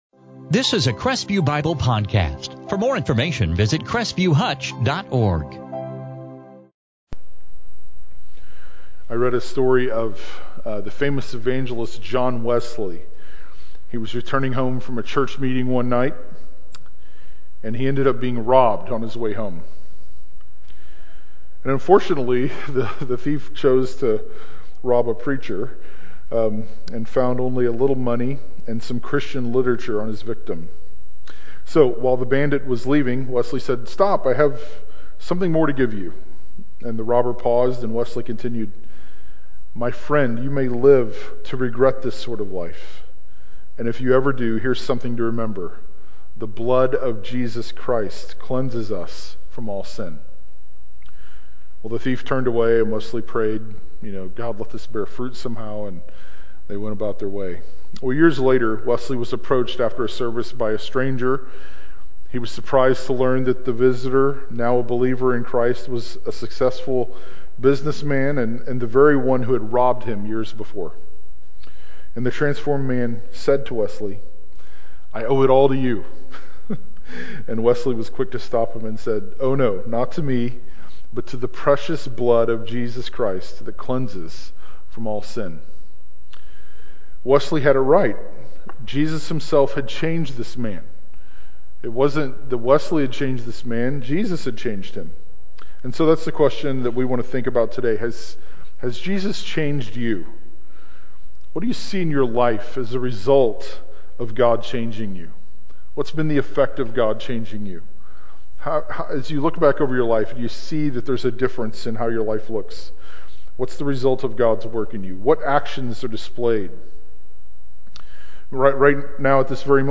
2018 Advent 2018 Luke Transcript In this sermon from Luke 2:8-20